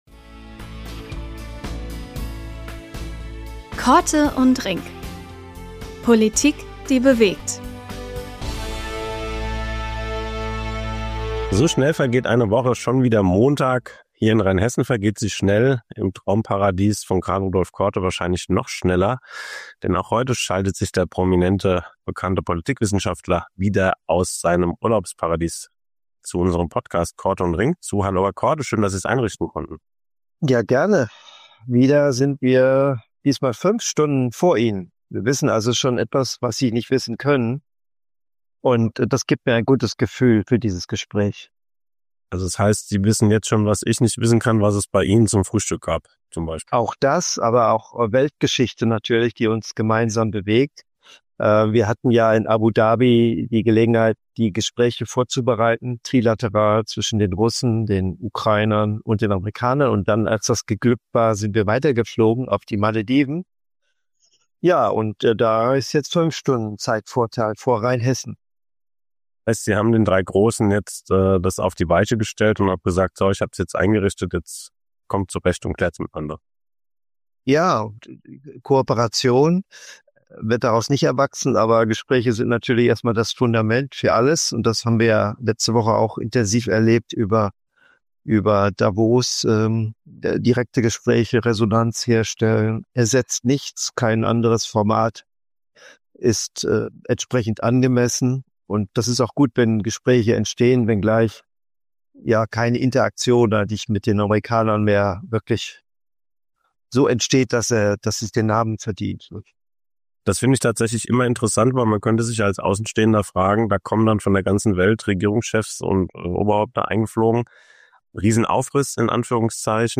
Prof. Dr. Karl-Rudolf Korte meldet sich diesmal von den Malediven